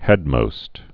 (hĕdmōst, -məst)